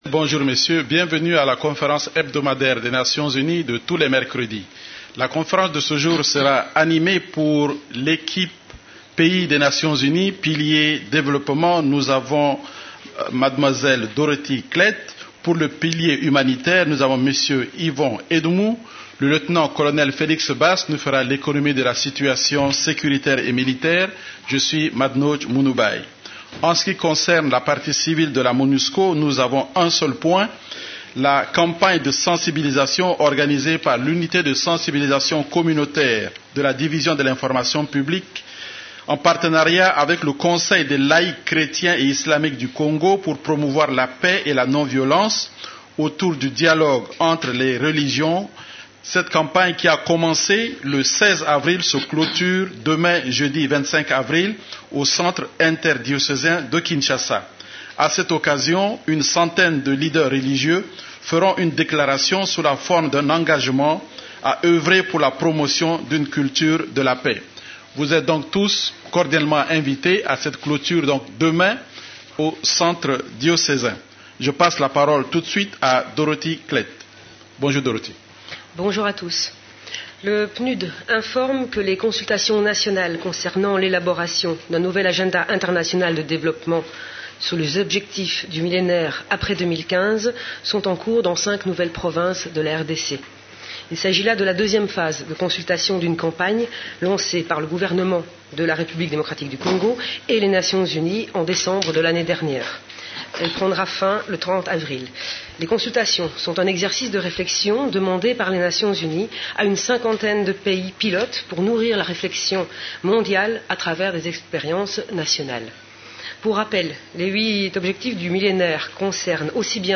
La conférence de presse hebdomadaire des Nations unies en RDC du mercredi 24 avril a porté sur les sujets suivants: